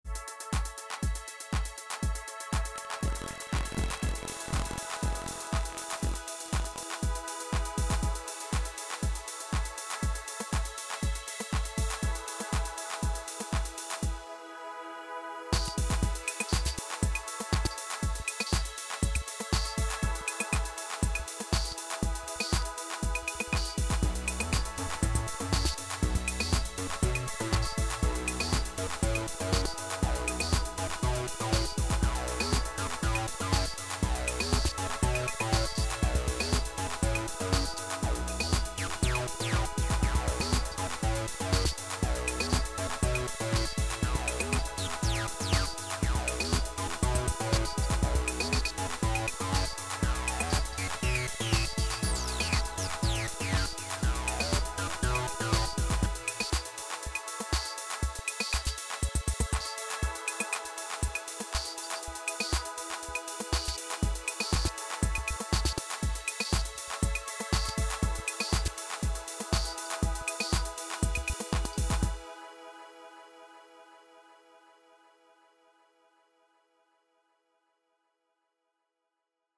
Got in about an hour long jam with a RE303 as a companion did immediately notice MIDI delay.
Definitely loving the Degrader FX and vibing with factory sounds more than my typical elektron experience.
Also just to see if it worked: this is recorded straight into voice memos via USB C to my iPhone
(idk what that weird buzzing is at the start)